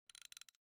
拖动放置.ogg